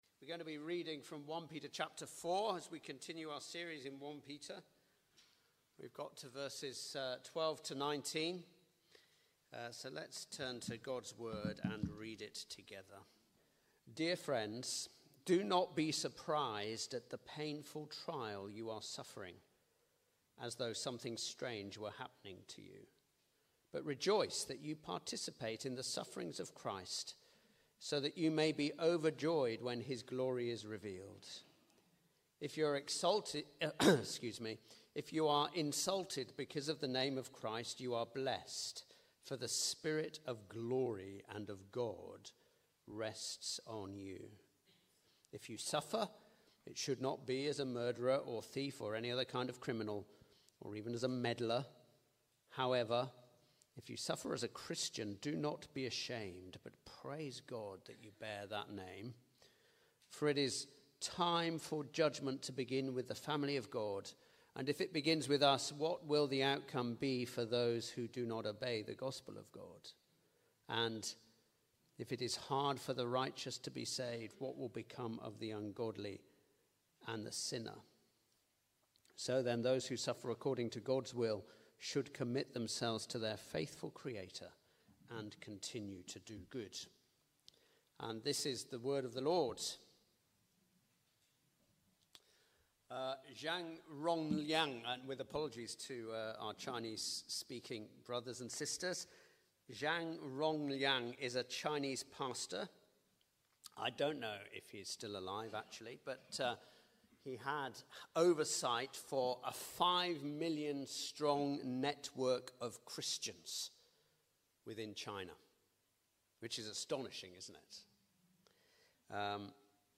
Sermon-12th-November-2023.mp3